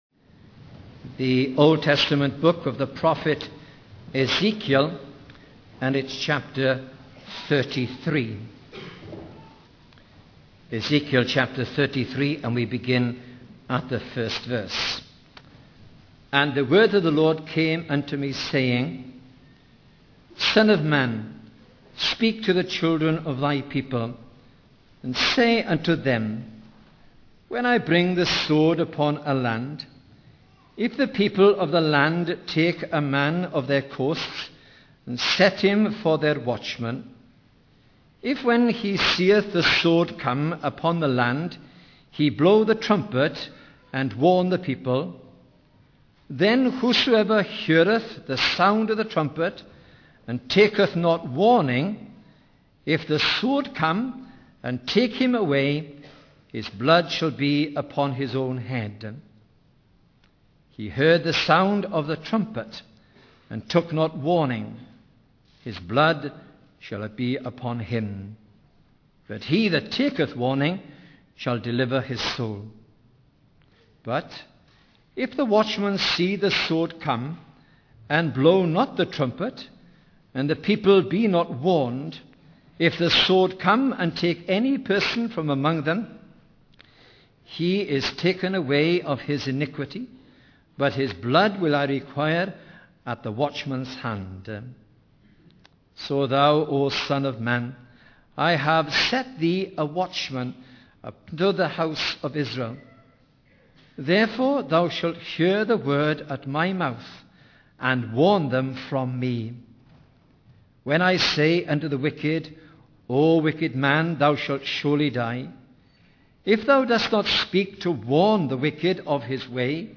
In this sermon, the preacher emphasizes the importance of repentance and turning away from sin. He highlights that God issues a universal invitation to all people to turn from their evil ways. The preacher emphasizes that God does not take pleasure in the death of the wicked, but rather desires for them to repent and turn to Him.